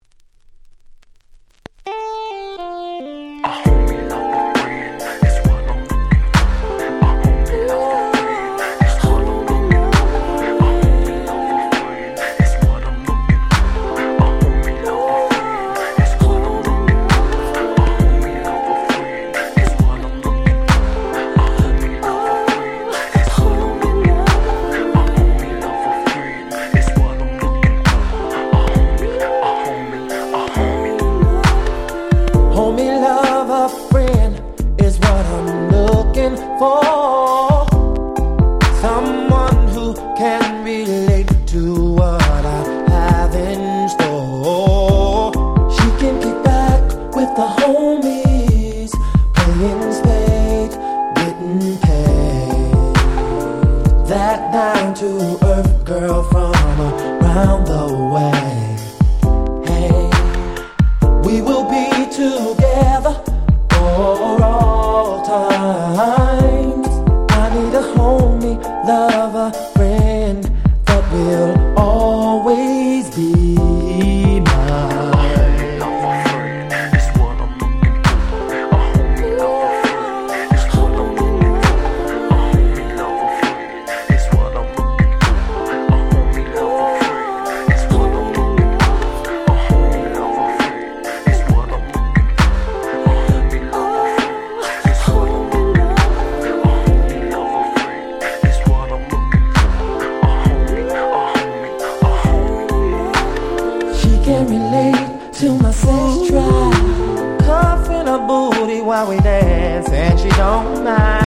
95’ Very Nice R&B !!